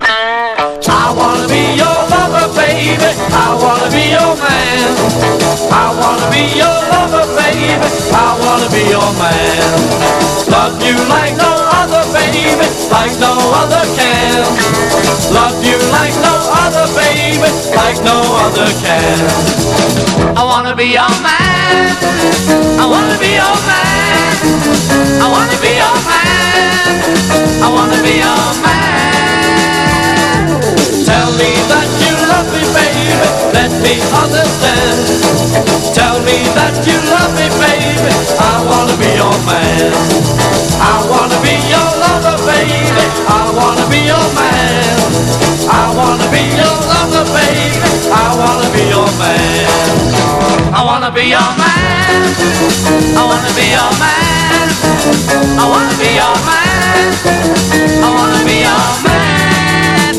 ROCK / 70'S / GUITAR / SWAMP / BLUES ROCK